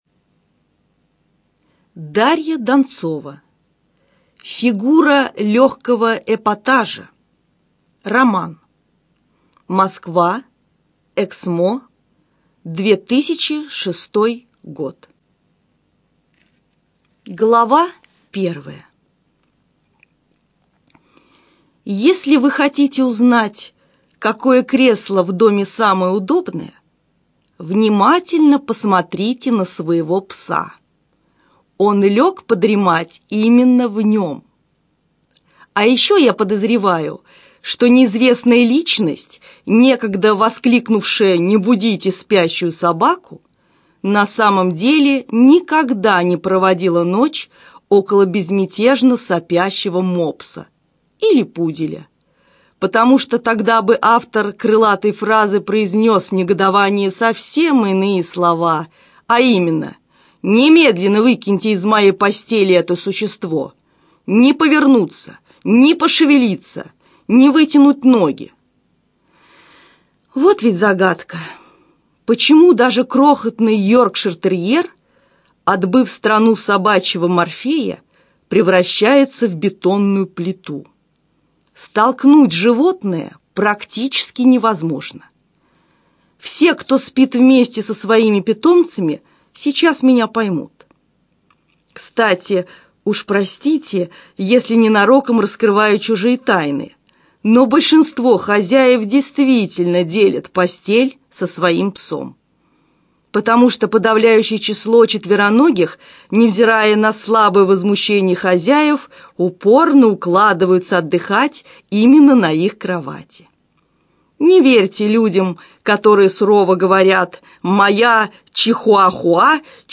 Аудиокнига Фигура легкого эпатажа - купить, скачать и слушать онлайн | КнигоПоиск